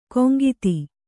♪ koŋgiti